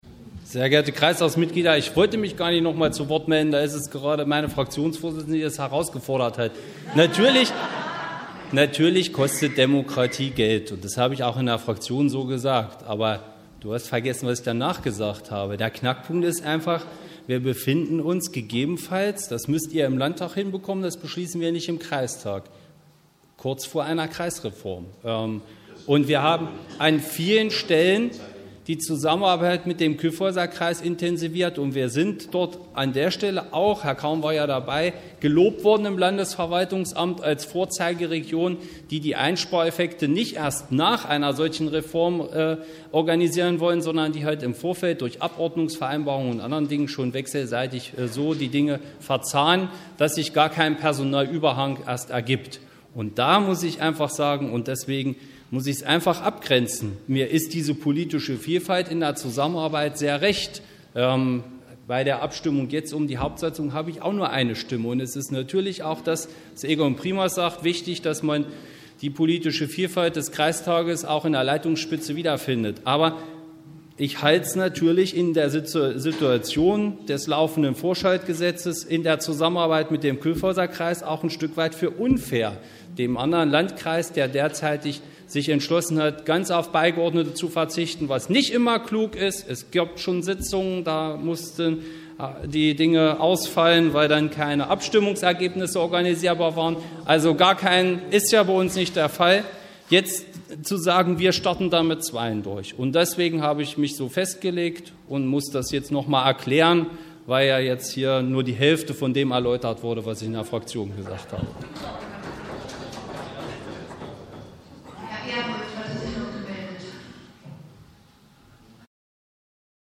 Wir haben hier die Wortmeldungen als Audiobeiträge für Sie zusammengestellt, die uns freundlicherweise das Bürgerradio ENNO zur Verfügung gestellt hat.